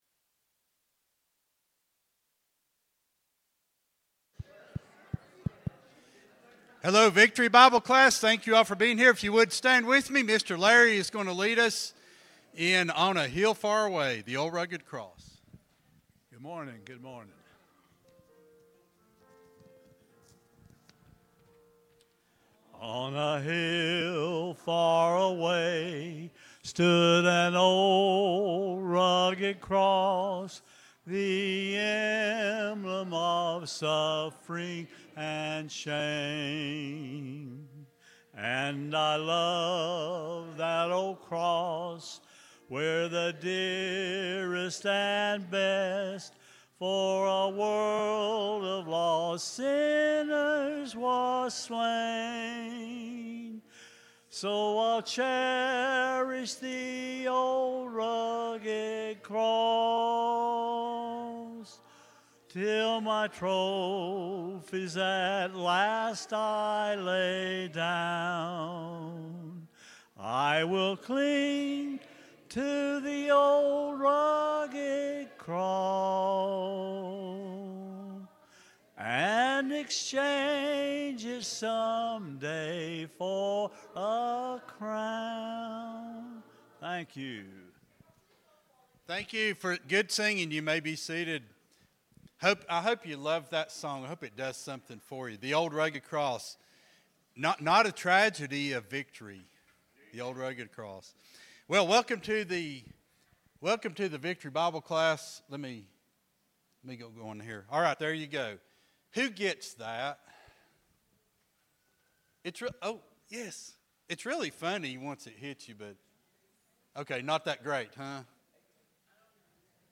Sunday School Lesson